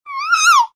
babywhimper1.ogg